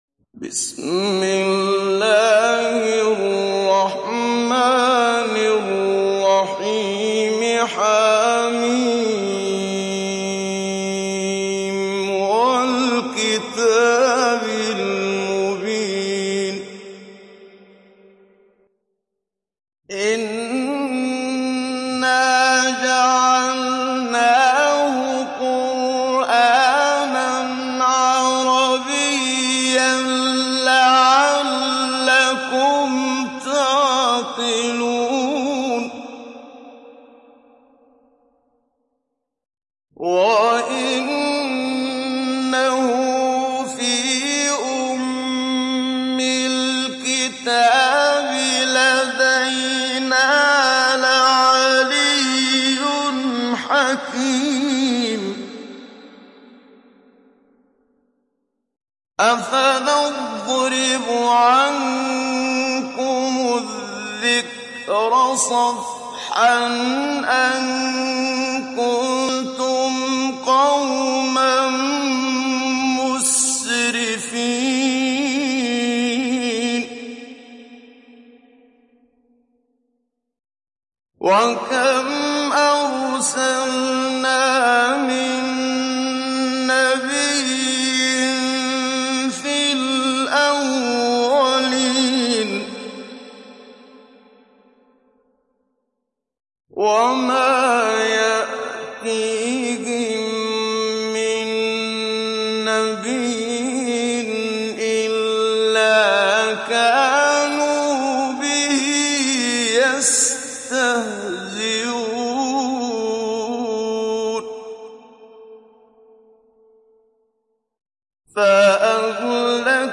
Download Surat Az Zukhruf Muhammad Siddiq Minshawi Mujawwad